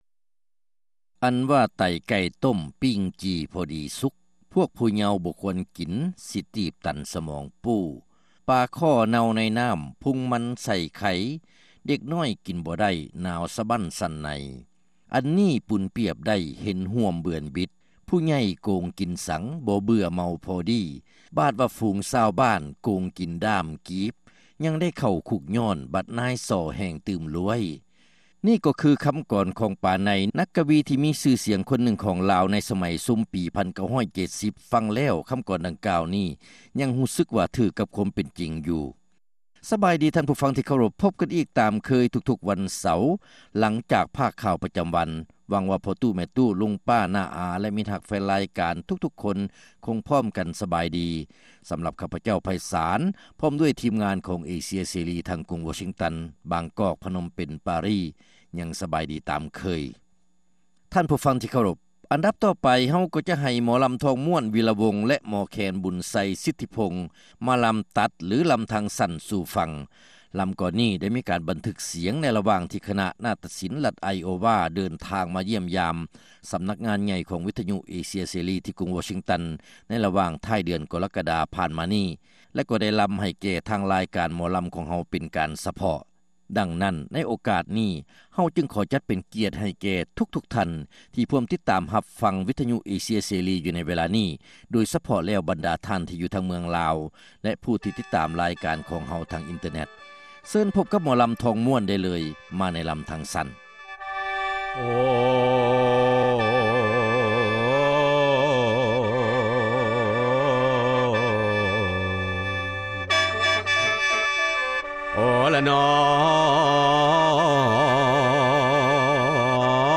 ຣາຍການໜໍລຳ ປະຈຳສັປະດາ ວັນທີ 29 ເດືອນ ກັນຍາ ປີ 2006